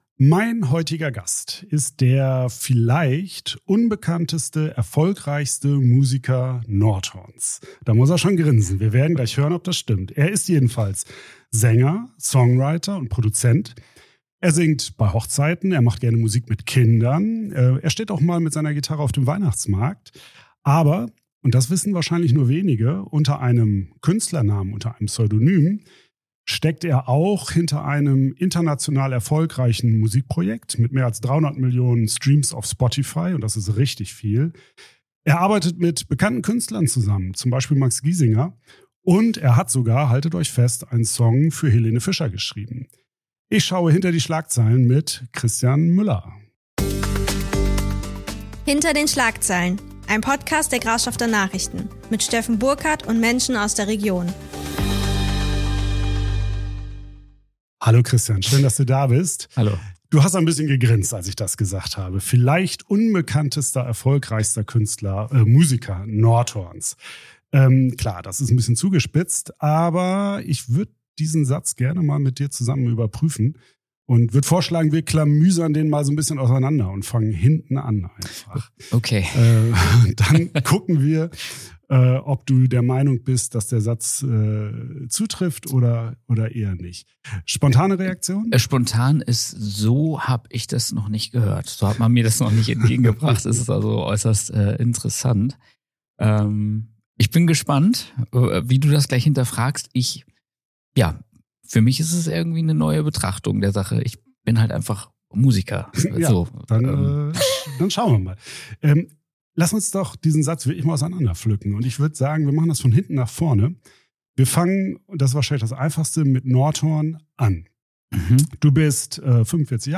Wie entsteht ein Song – und braucht es Songwriter überhaupt noch im Zeitalter von KI? Ein Gespräch über Kreativität, Familie, Sichtbarkeit, musikalische Visionen und die leisen Wege zum großen Erfolg.